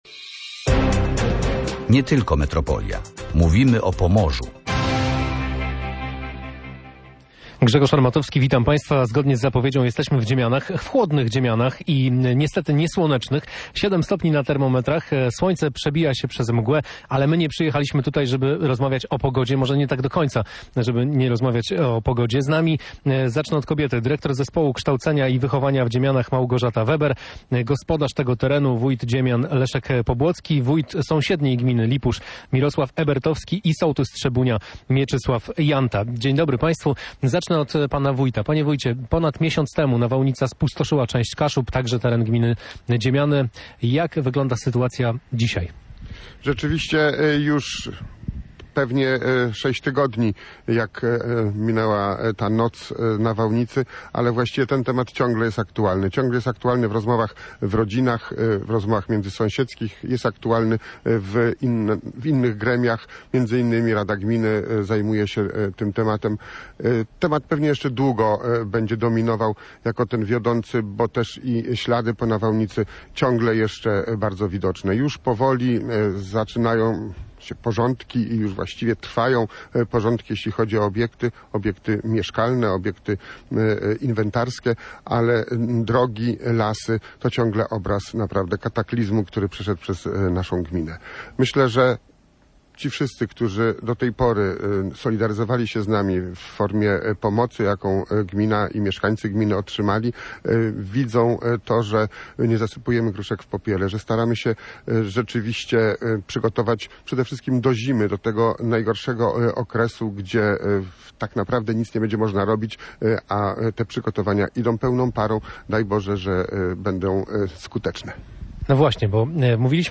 gościnnie z Dziemian